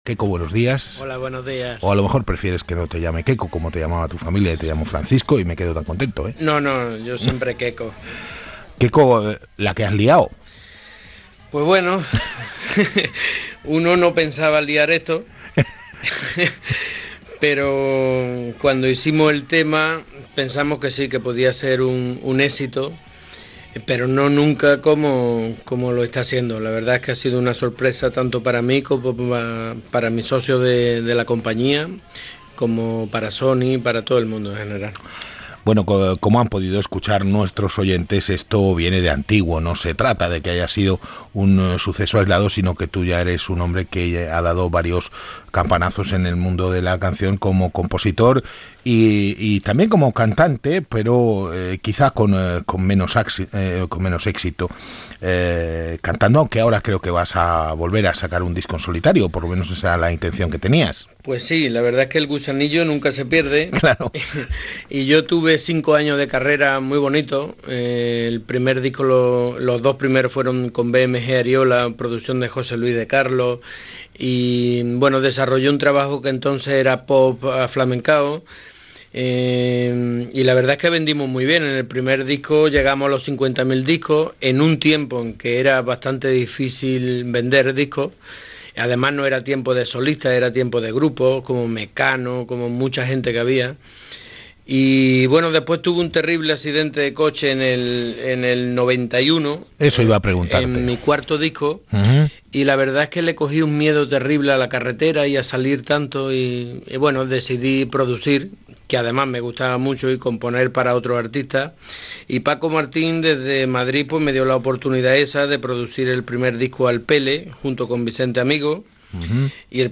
Entrevista
Info-entreteniment